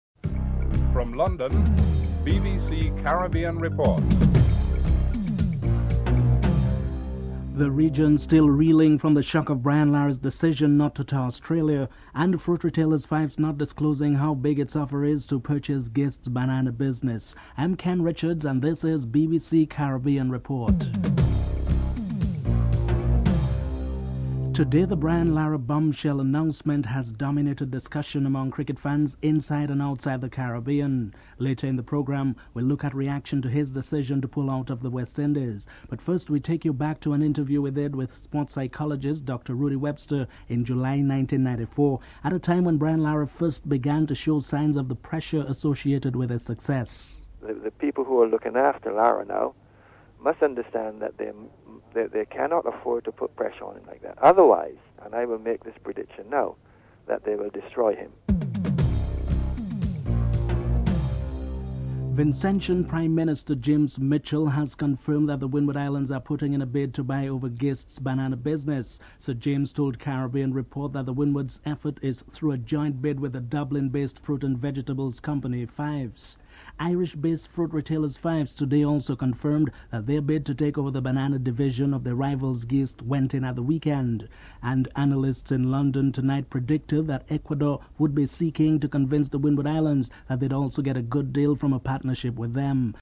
6. Sport editors from Jamaica and Trinidad have their say on the matter of Lara's decision (05:50-09:59)